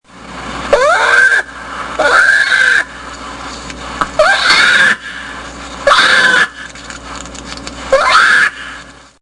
Hha, Hha, Hha !!
Hha Hha Hha !!! Jamais je n’aurais deviné que c’est ainsi que les oursons appellent leurs mamans.
ourson noir en détresse, mais le son était un peu plus bas et plus dur, peut-être qu’il s’agissait de grizzlis ou d’oursons un peu plus âgés?
Cub_distress.mp3